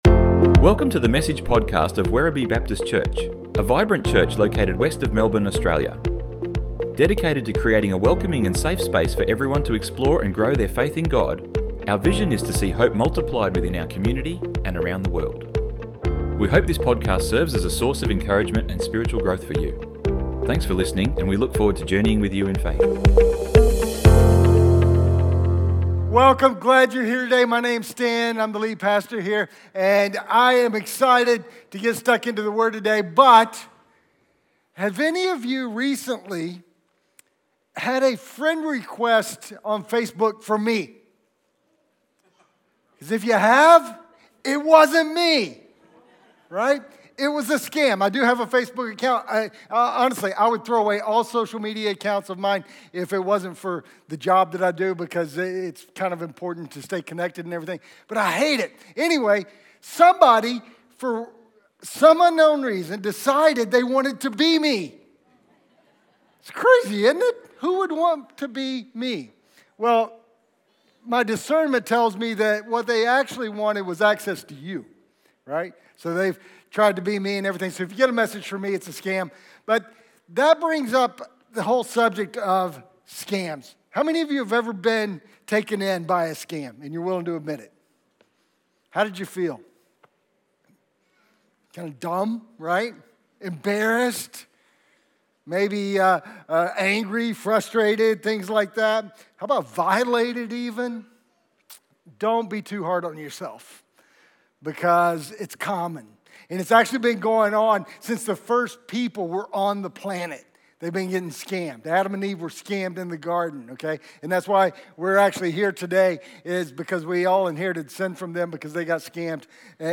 Download Download Sermon Notes 06-Assembly-required-How-to-spot-a-scammer.pdf 06 - online notes - Assembly Required - not falling for fakes.doc Have any of you ever been the victim of a scam?